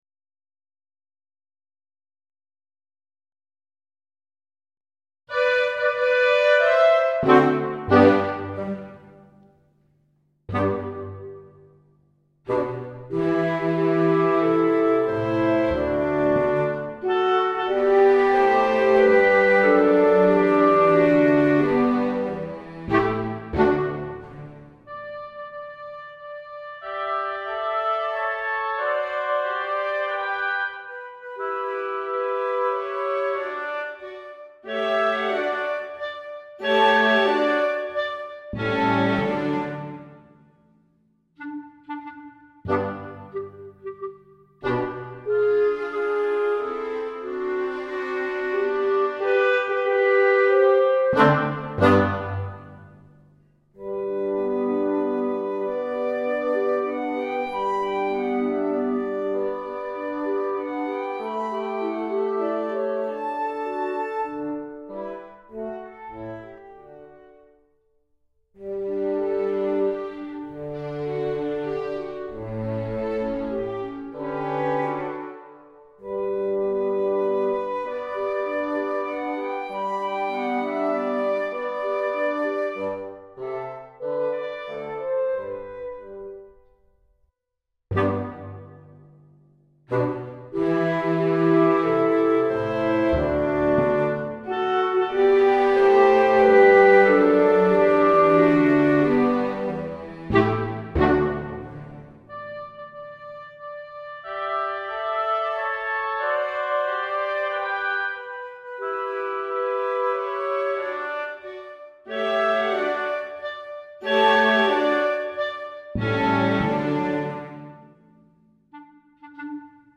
55 Minuet and Trio (Backing Track)